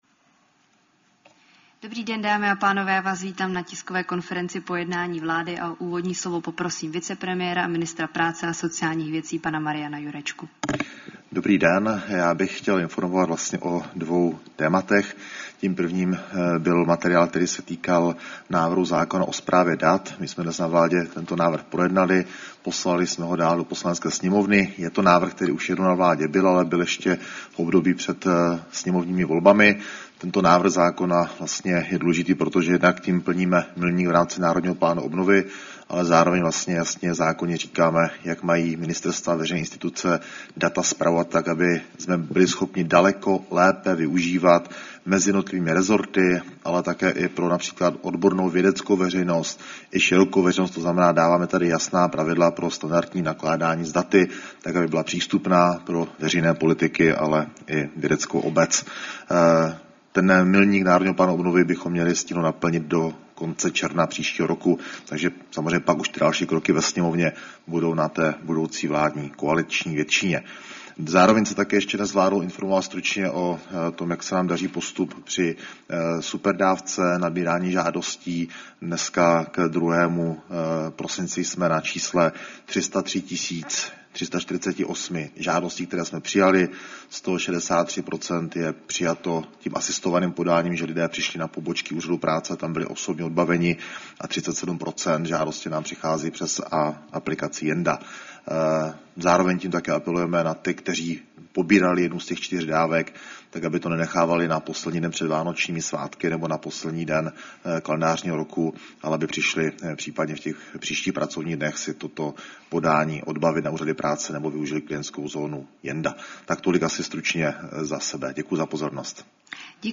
Tisková konference po jednání vlády, 3. prosince 2025